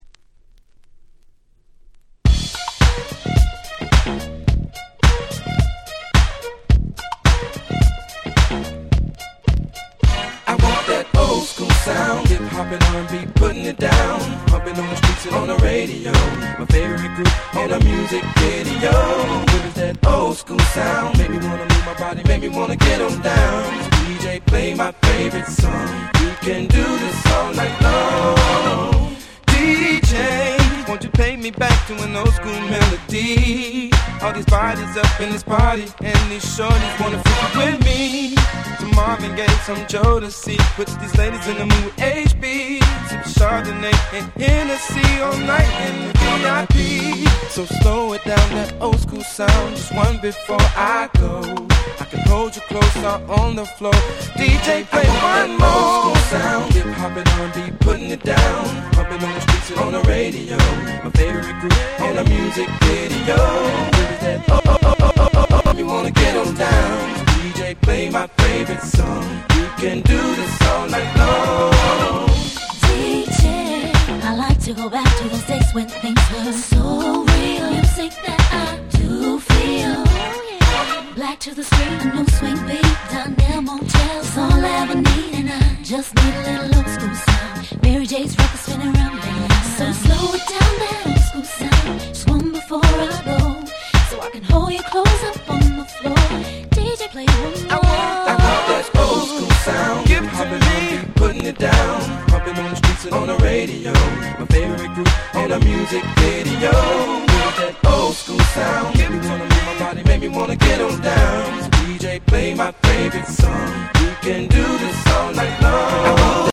07' Nice Europe R&B !!
00's キャッチー系